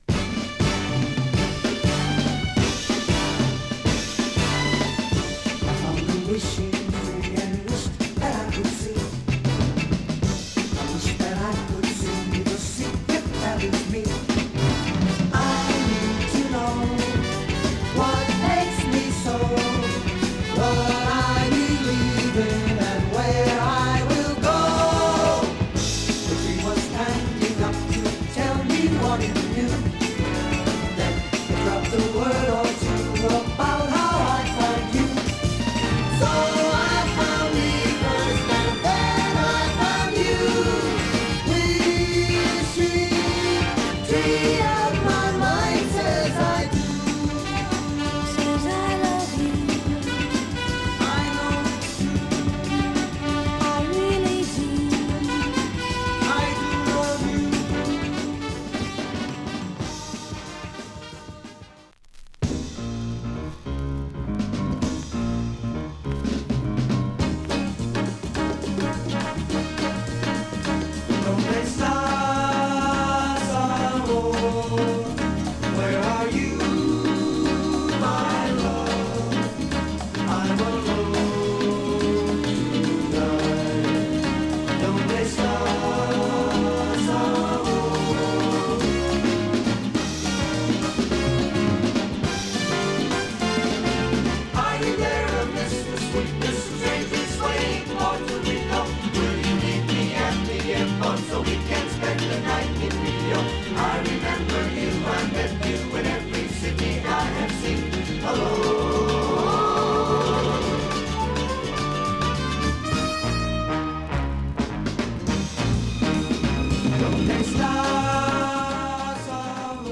正体不明の極上ソフトロック！！
今回盤質がイマイチ(Sample本盤より...軽いチリパチ)なのでお買い得にて。